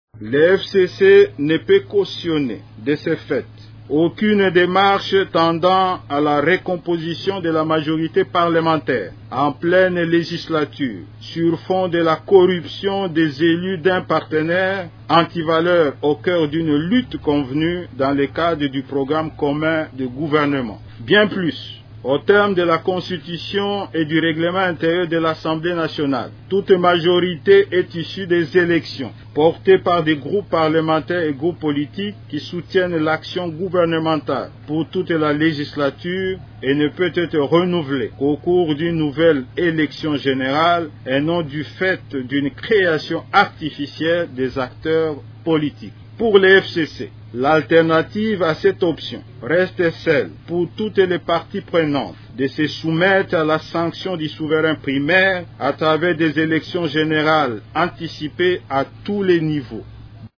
Ecoutez l’extrait de cette déclaration finale lue par le coordonnateur de la plateforme de Joseph Kabila, Néhémie Mwilanya :